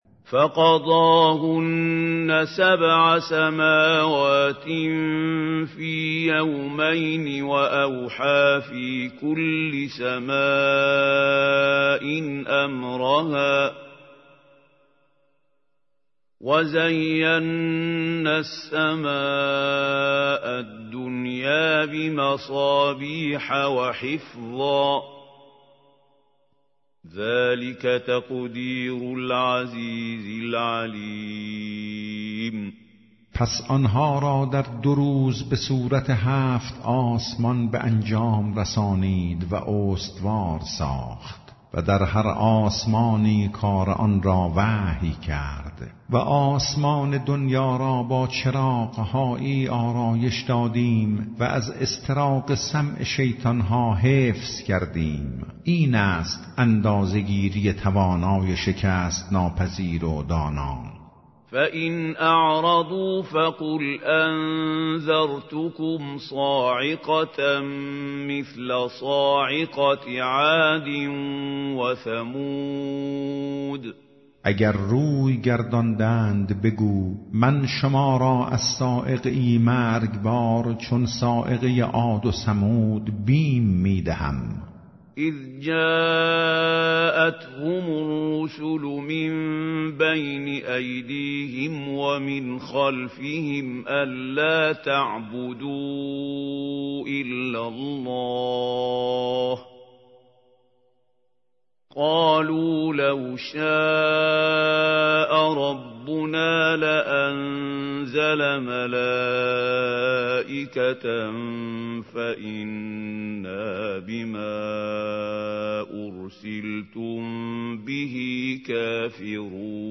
ترجمه گویای قرآن کریم - جزء ۲۴